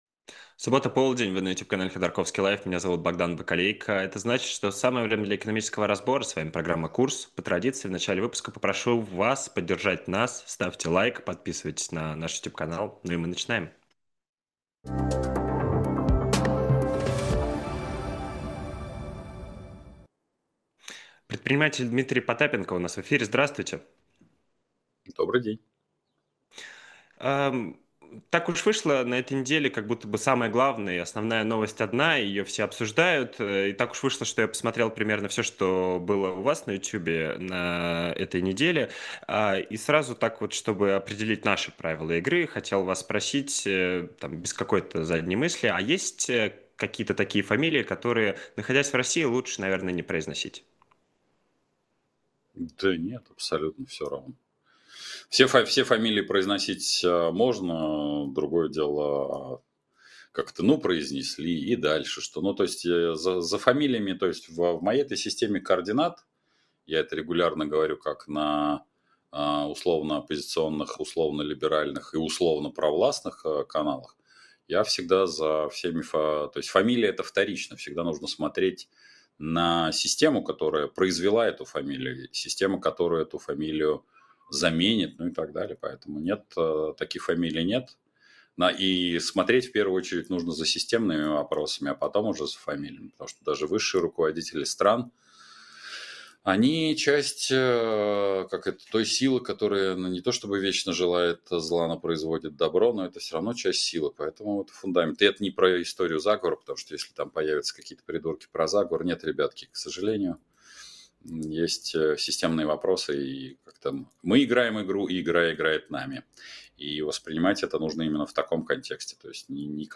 На эти вопросы отвечает предприниматель Дмитрий Потапенко.